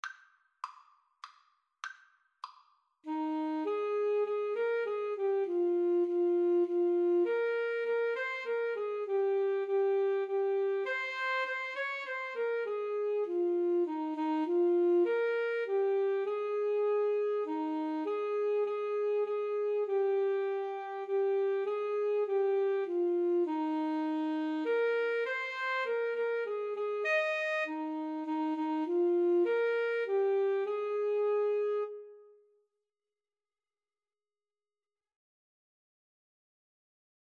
Moderato
3/4 (View more 3/4 Music)